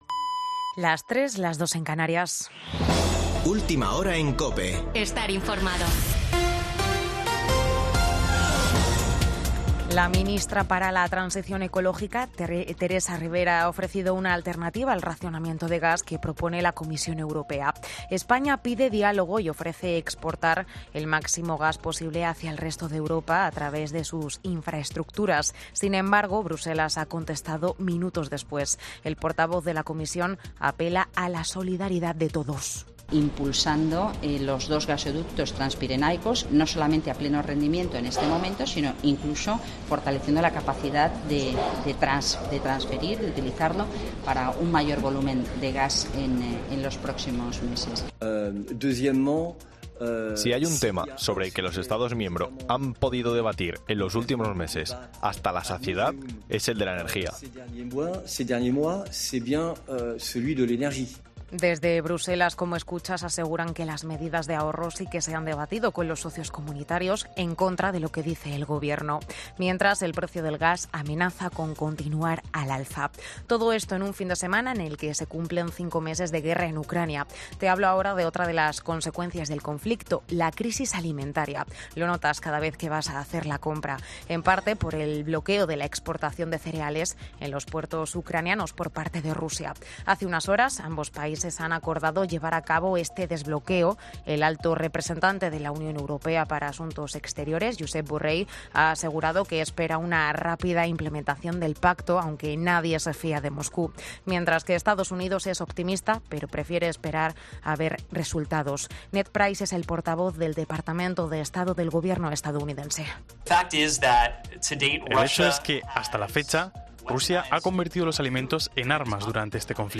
Boletín de noticias de COPE del 23 de julio de 2022 a las 03.00 horas